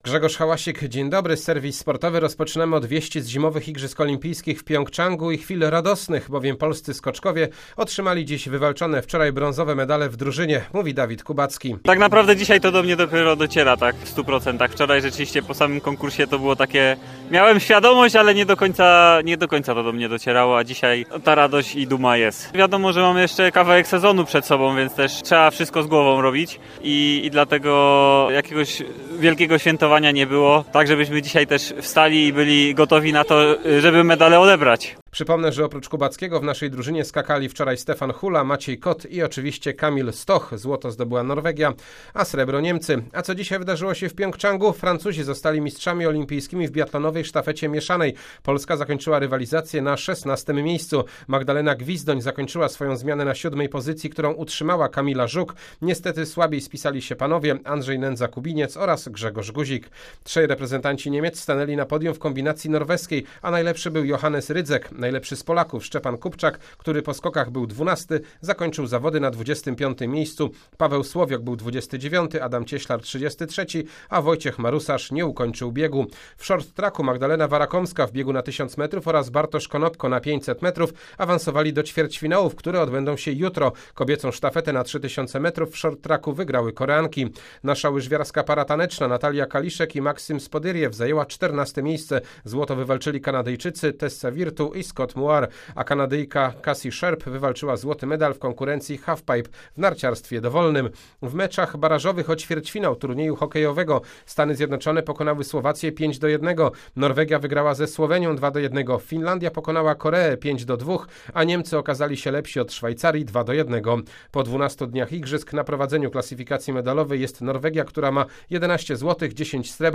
20.02 serwis sportowy godz. 19:05